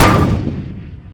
zomgShoot.ogg